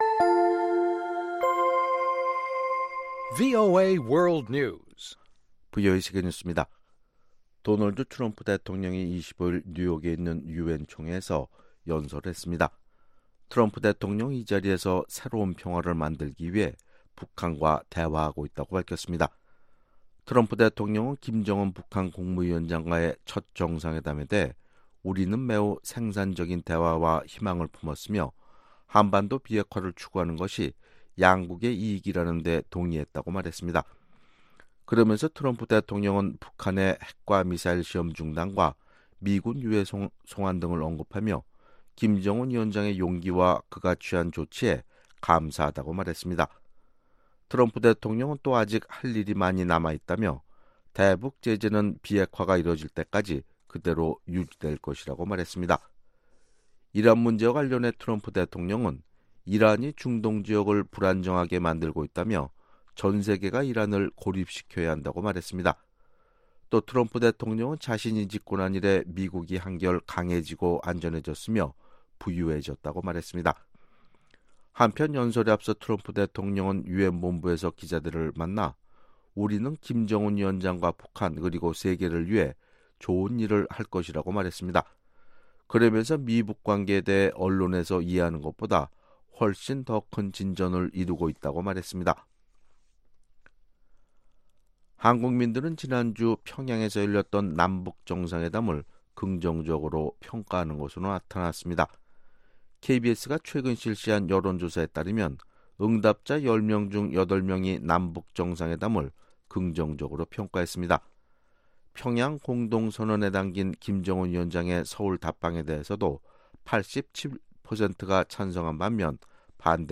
VOA 한국어 아침 뉴스 프로그램 '워싱턴 뉴스 광장' 2018년 9월 26일방송입니다. 트럼프 대통령이 김정은 북한 국무위원장과 그리 머지 않은 미래에 정상회담을 가질 것이라고 밝혔습니다. 마이크 폼페오 국무장관은 2차 미-북 정상회담 준비를 위해 방북할 계획이라며 올해 안에 방문할 가능성을 시사했습니다.